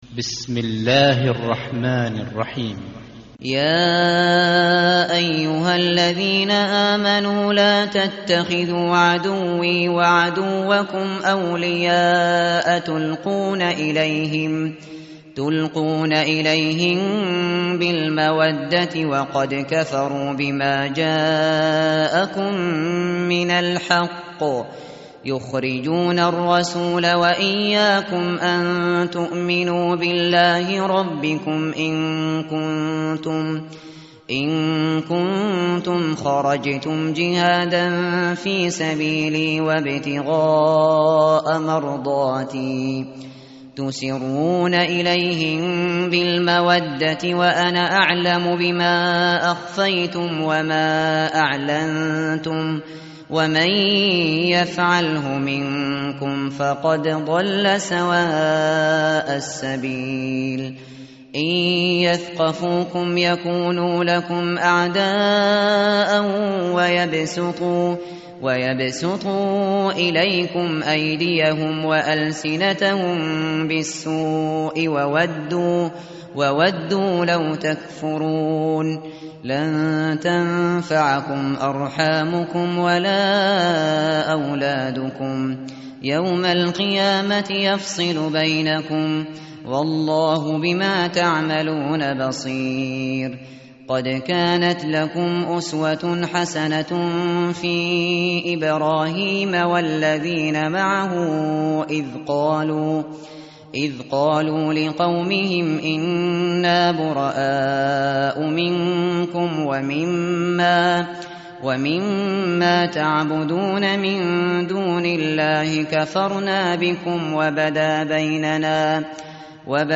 tartil_shateri_page_549.mp3